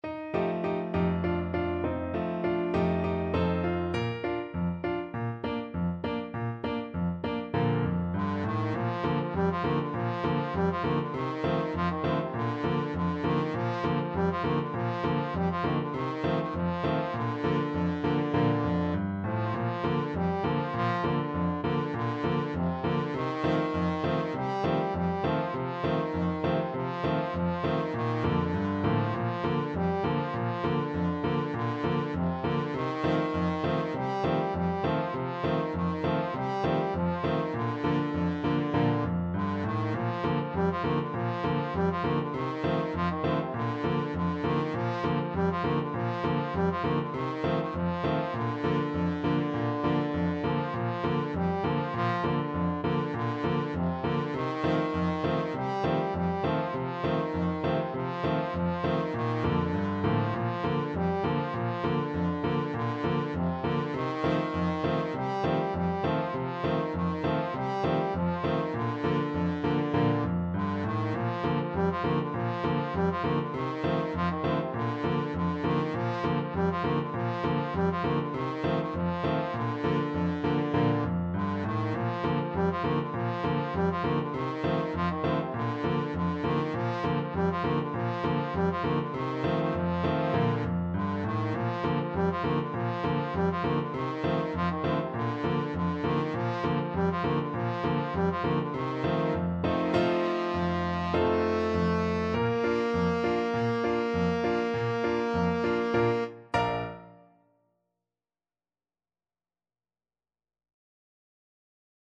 Free Sheet music for Trombone
Trombone
Bb major (Sounding Pitch) (View more Bb major Music for Trombone )
2/4 (View more 2/4 Music)
~ = 100 Allegro moderato (View more music marked Allegro)
C4-Bb4
Traditional (View more Traditional Trombone Music)
world (View more world Trombone Music)